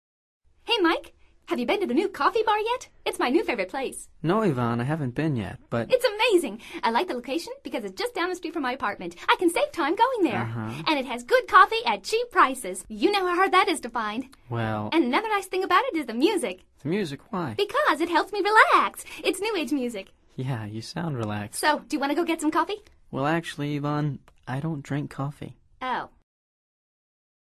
Escucha atentamente esta conversación entre Yvonne y Mike y selecciona la respuesta más adecuada de acuerdo con tu comprensión auditiva.